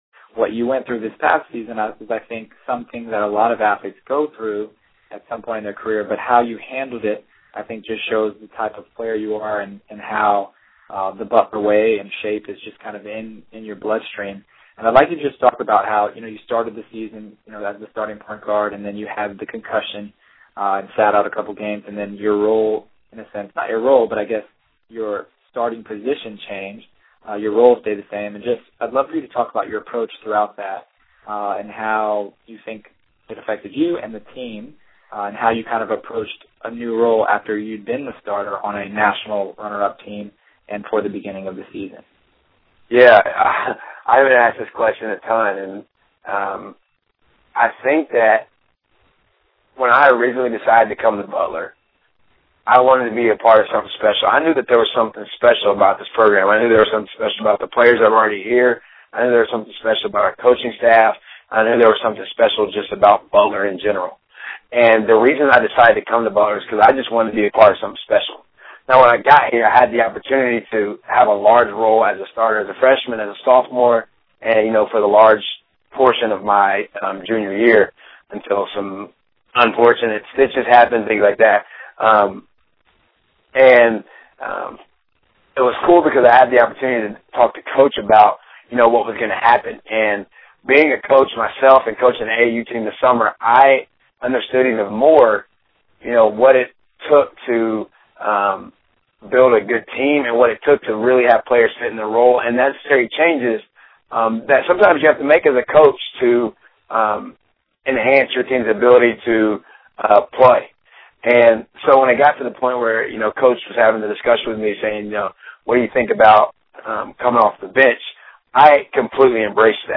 My hope is that you all found and will find value in the PGC Online Training quarterly interviews with college athletes and coaches while you are in the midst of training in order to reach your basketball aspirations.